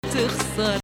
Hijaz 1